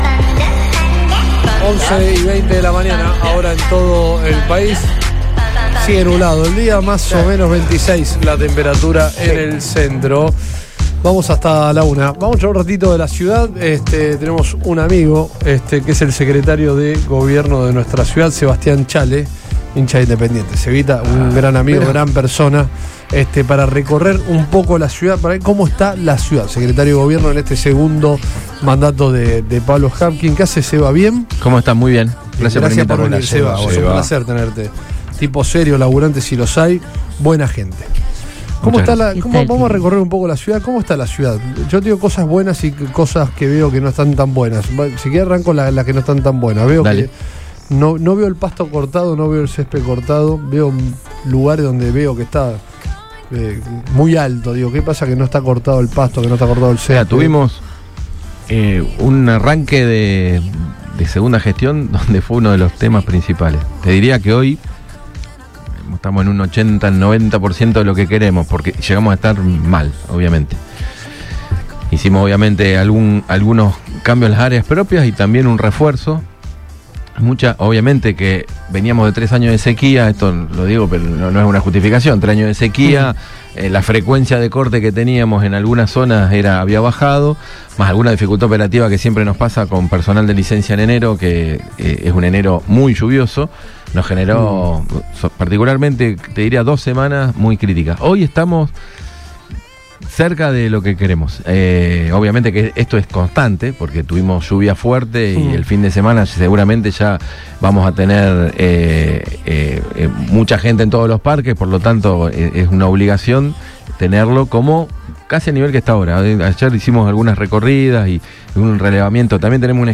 Sebastián Chale, secretario de gobierno de la Municipalidad de Rosario